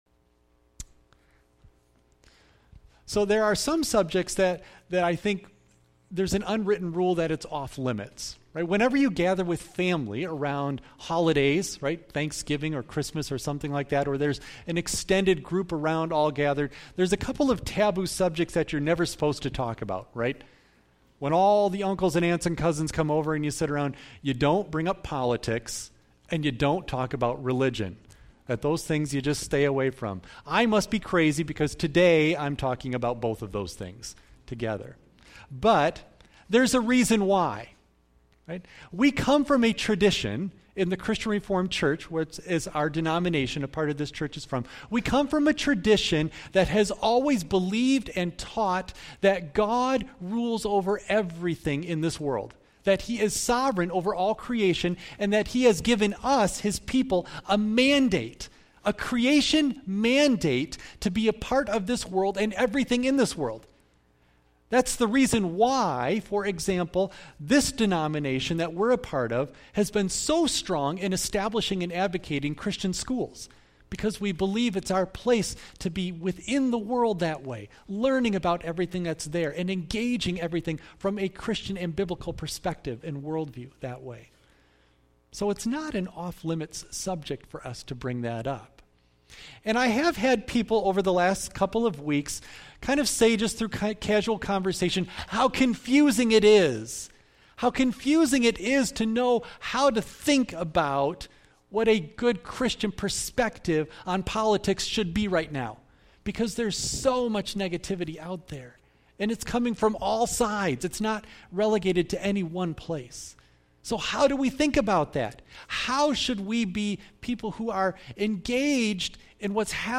1 John 4:7-21 Service Type: Sunday AM Bible Text